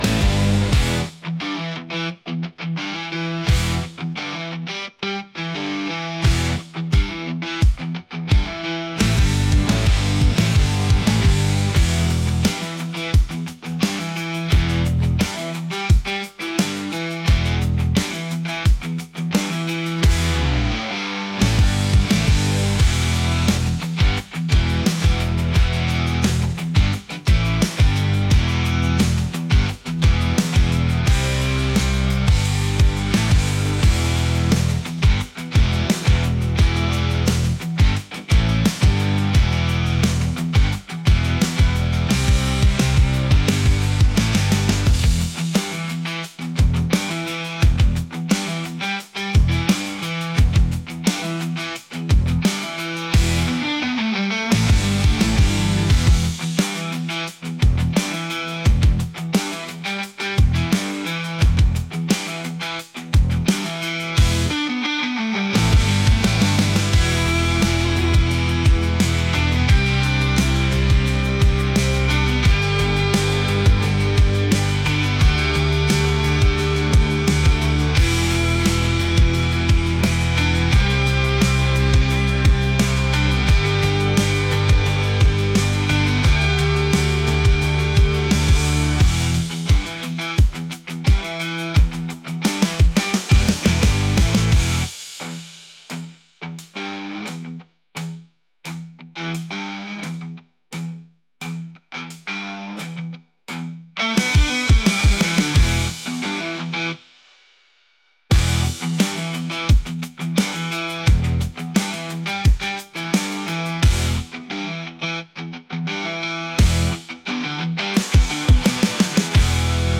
rock | intense